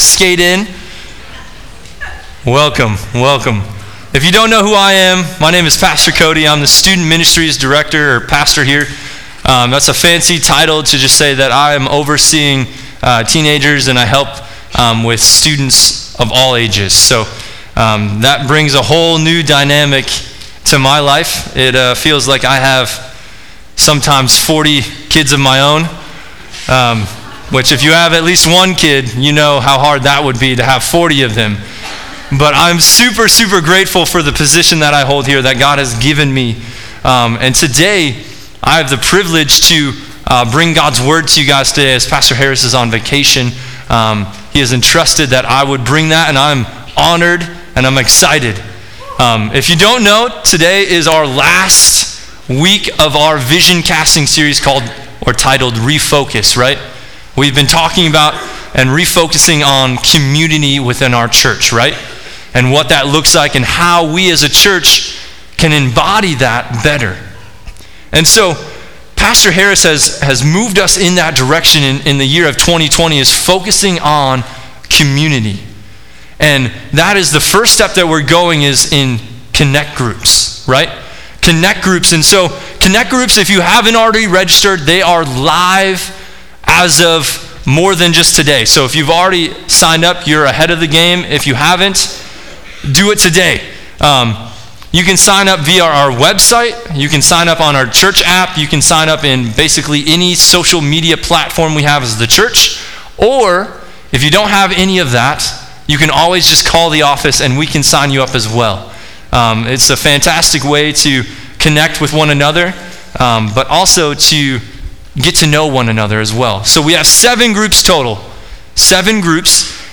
Service Type: Sunday AM « Trustworthy